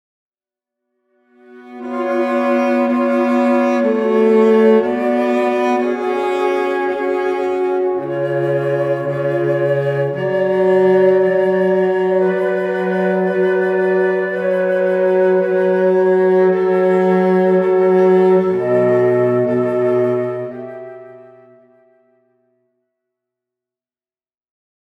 en ré majeur-Adagio